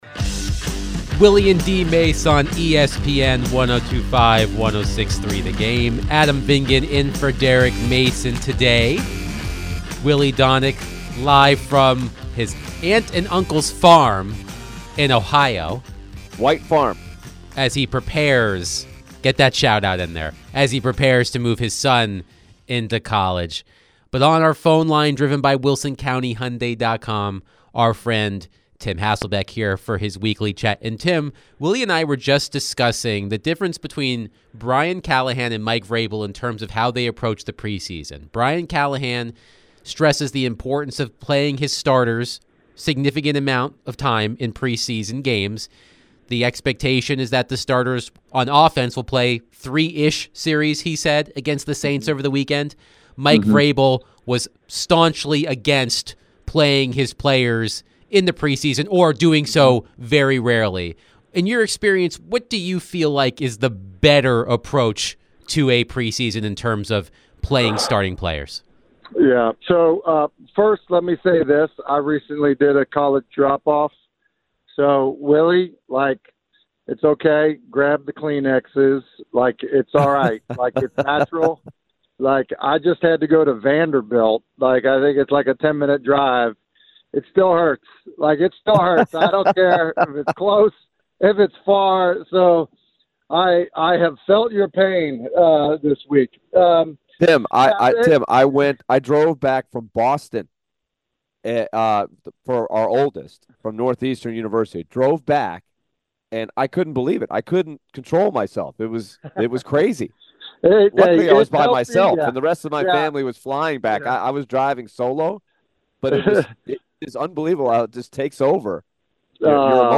ESPN NFL analyst Tim Hasselbeck joined the show and shared his thoughts about the coaching comparisons of Brian Callahan and Mike Vrabel. Later in the conversation, Tim was asked about Will Levis and his development with the team.